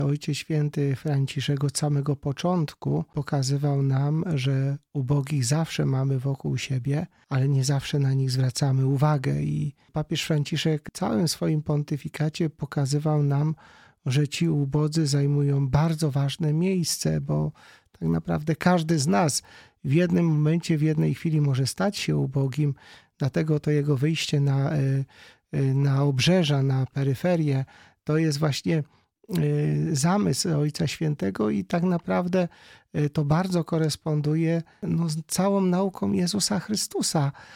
W rozmowie z Radiem Rodzina opowiedział nie tylko o wspomnieniach jakie pozostaną w nim po papieżu, ale też o przesłaniu jego pontyfikatu.